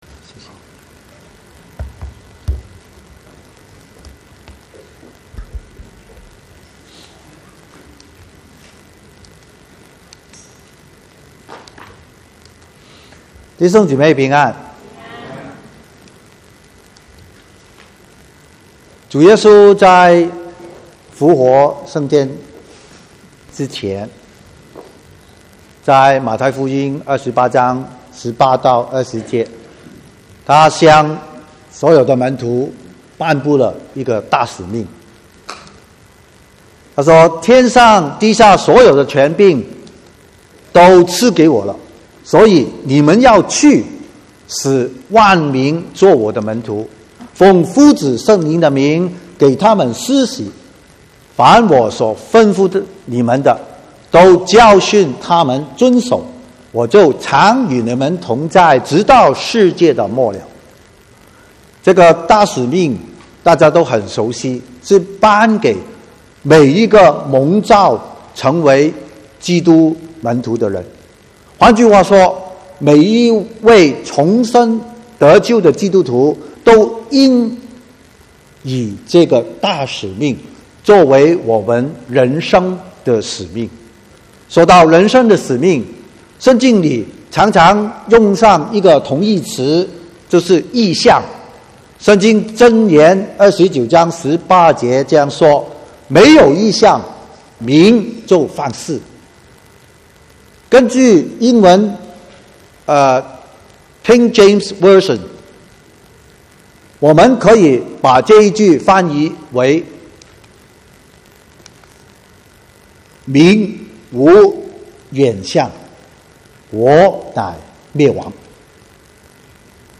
10/6/2018 國語堂講道